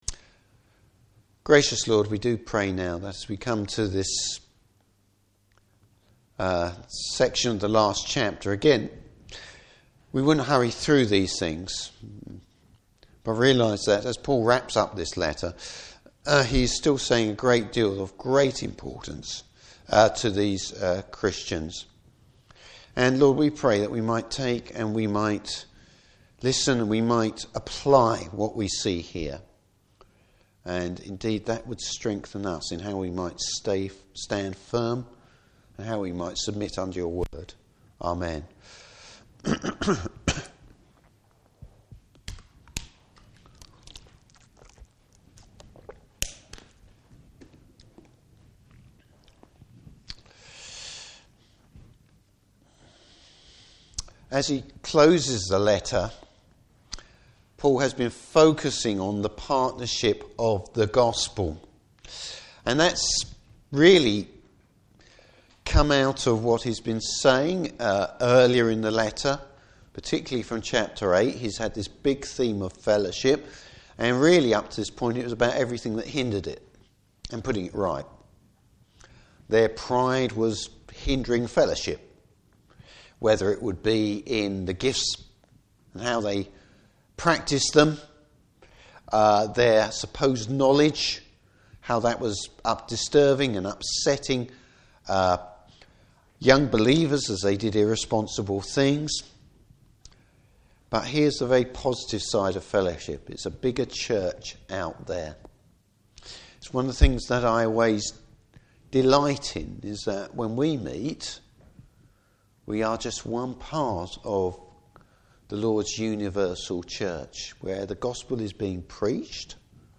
Service Type: Morning Service Maturing in the faith. Topics: Standing Firm for the Gospel.